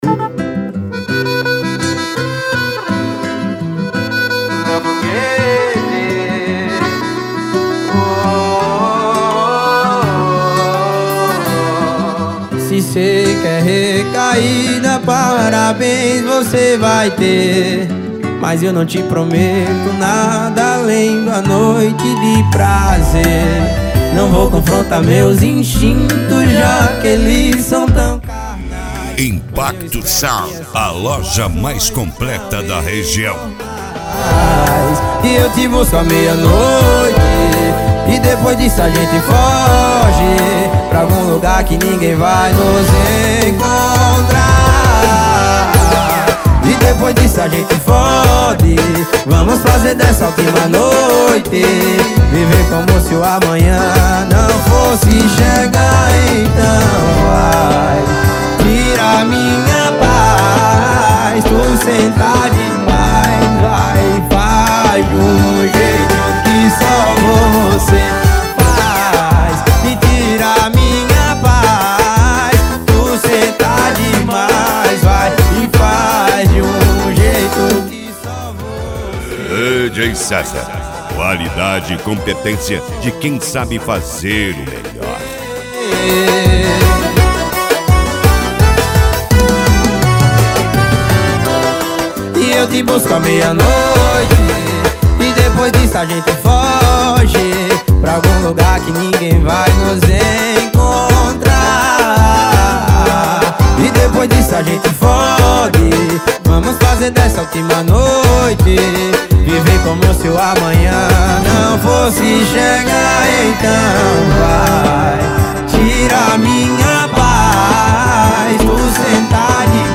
Modao
SERTANEJO
Sertanejo Raiz
Sertanejo Universitario